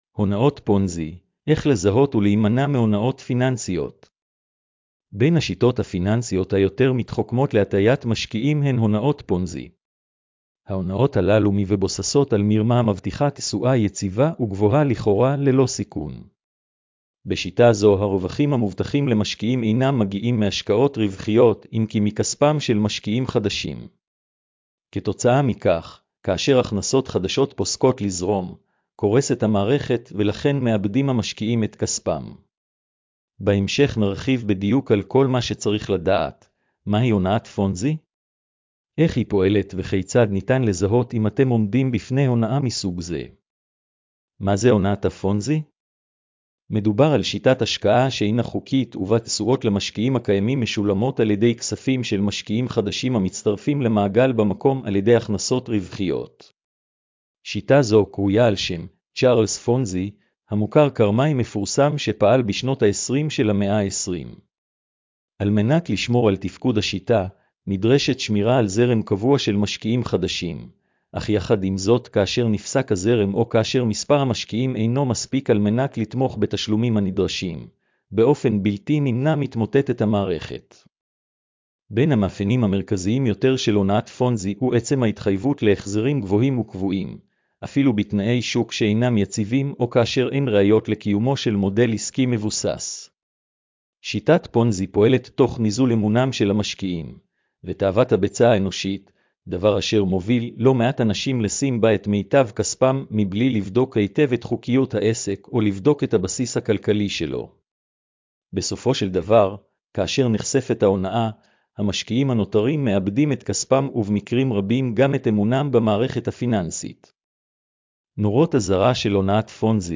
השמעת המאמר לכבדי ראייה: